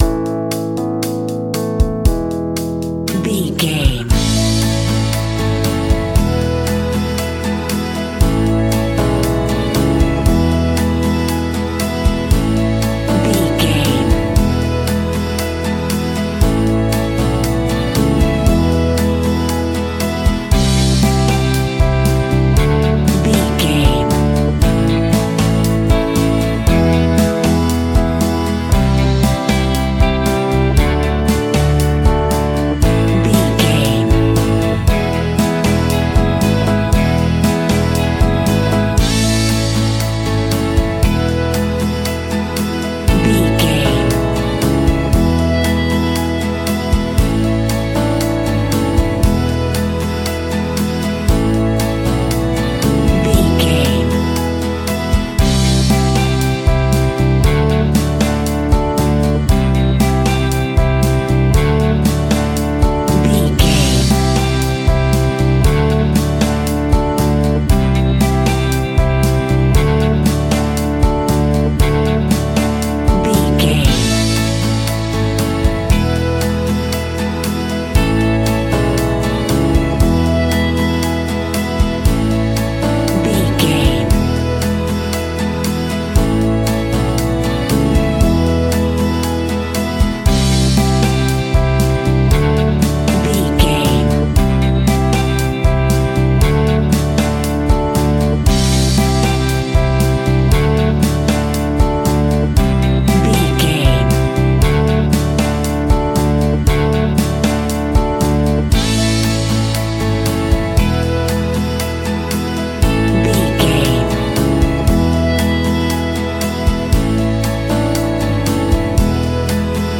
Ionian/Major
pop
pop rock
synth pop
pop rock instrumentals
happy
upbeat
bright
bouncy
drums
bass guitar
electric guitar
keyboards
hammond organ
acoustic guitar
percussion